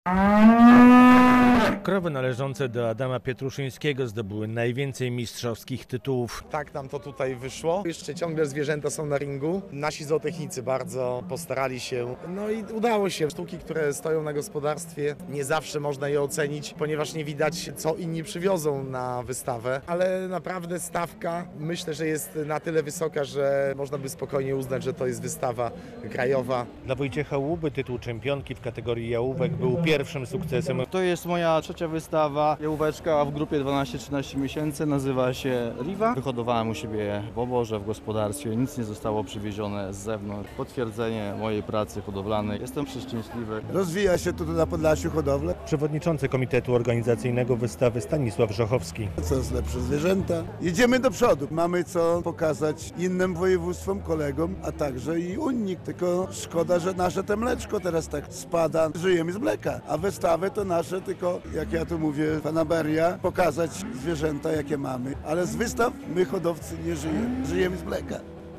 Hodowcy prezentują swoje zwierzęta na wystawie w Szepietowie [zdjęcia]
relacja